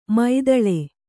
♪ maidaḷe